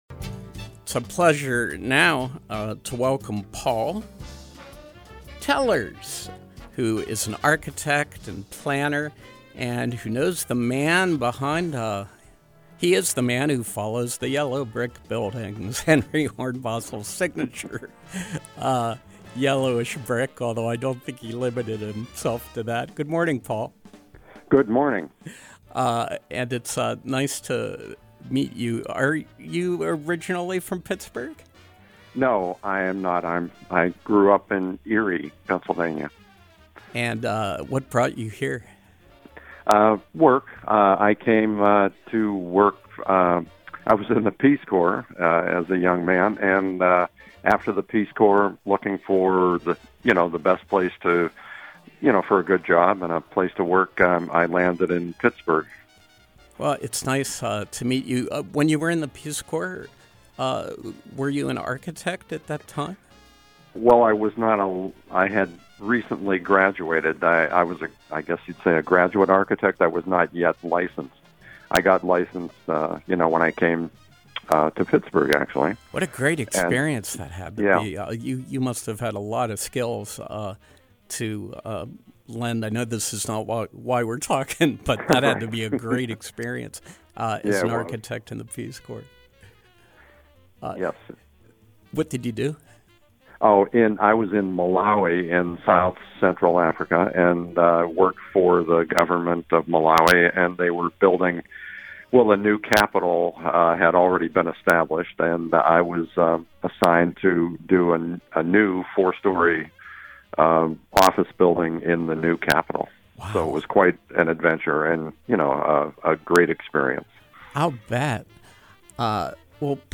Interview: Henry Hornbostel’s Impact on Pittsburgh